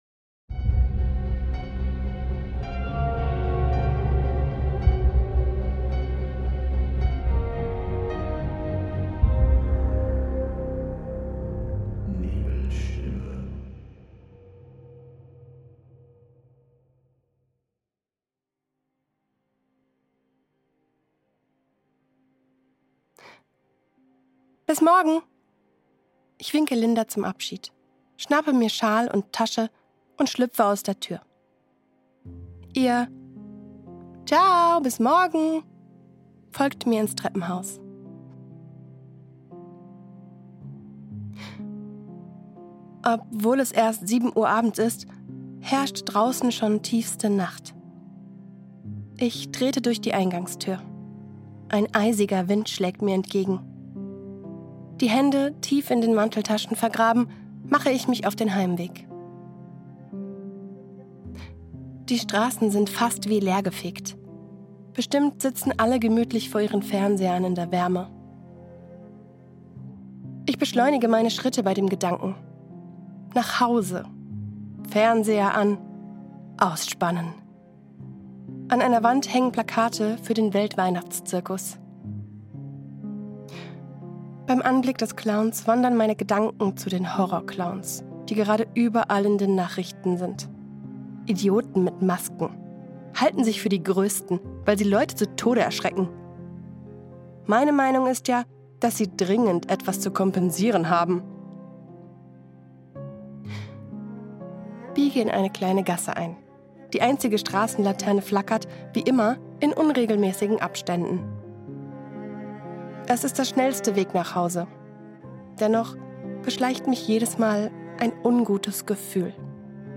Und verzeih mir bitte, ich war krank bei der Aufnahme! :)